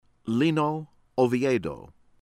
OVELAR, BLANCA BLAHN-kah   oh-vee-LAHR